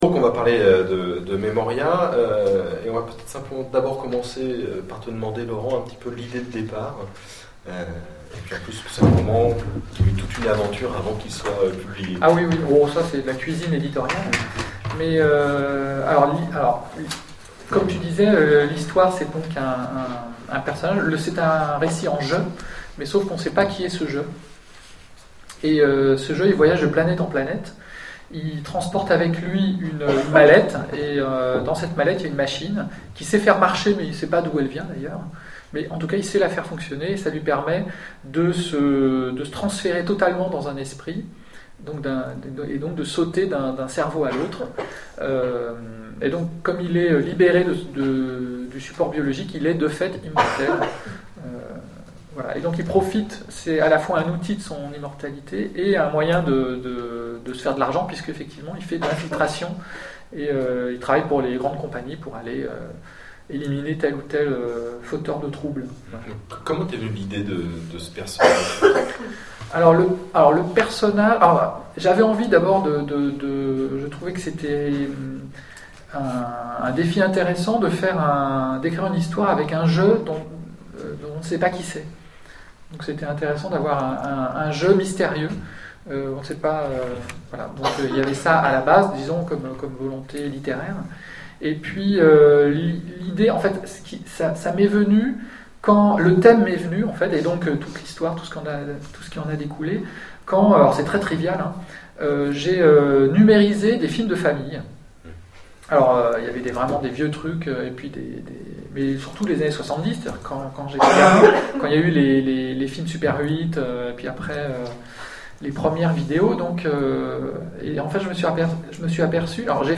Mots-clés Mémoire Rencontre avec un auteur Conférence Partager cet article